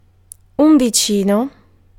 Ääntäminen
UK : IPA : /nɪə(ɹ)/ US : IPA : /ˈnɪɹ/